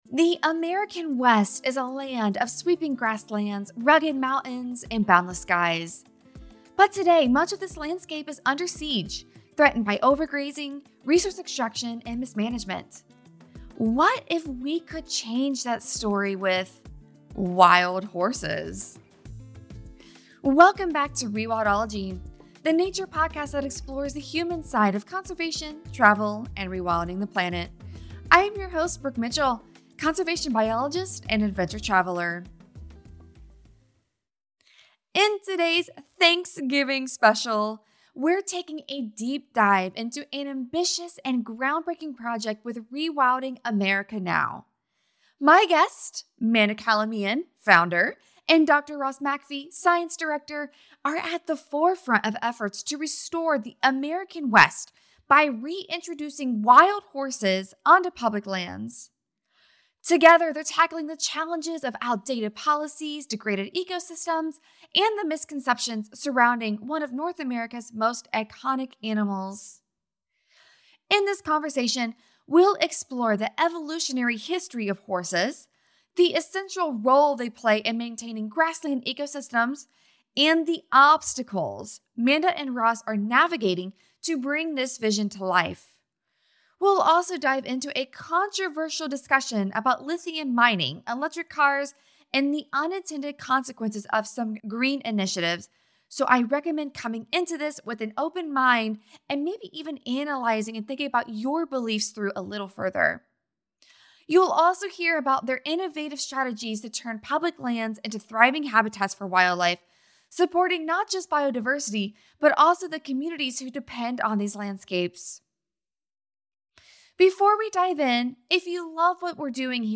Together, they discuss the evolutionary history of horses, their role as keystone species in grassland ecosystems, and the systemic challenges facing conservation on public lands. The conversation also delves into the controversial topics of lithium mining, electric cars, and the broader implications of balancing green initiatives with land restoration.